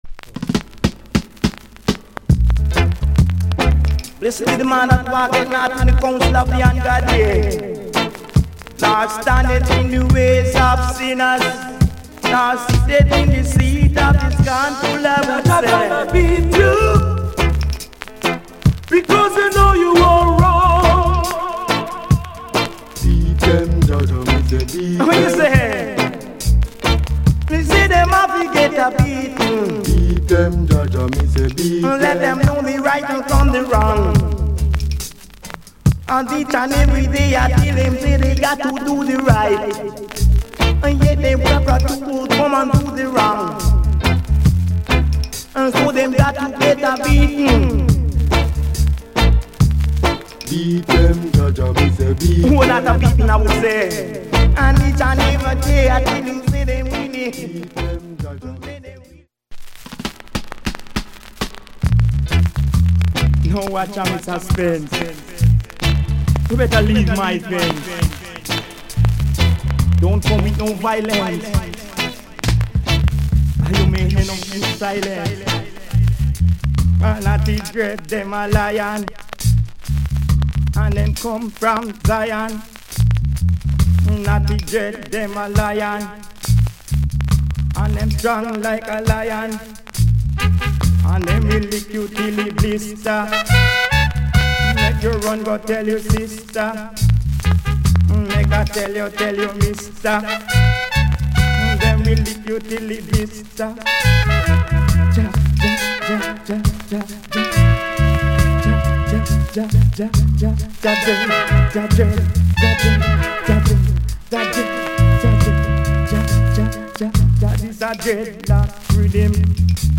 Genre Reggae70sEarly / Male Vocal